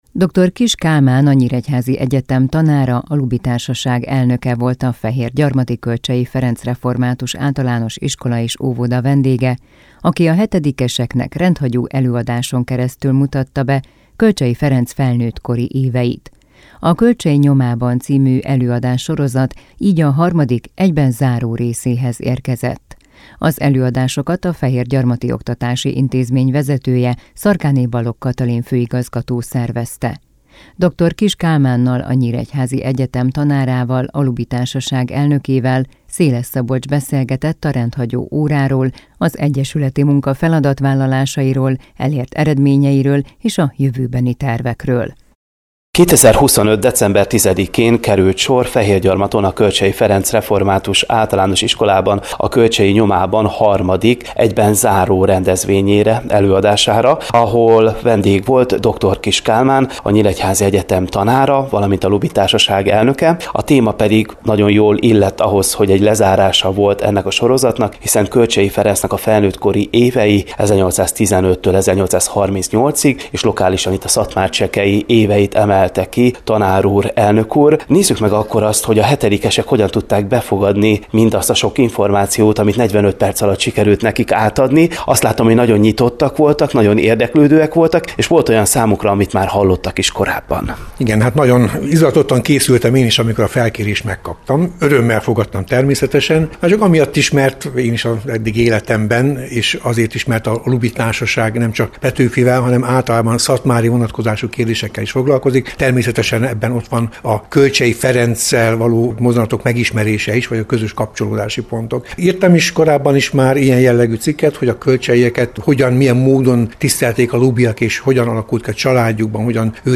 Rendhagyó előadás Kölcsey Ferenc felnőttkori éveiről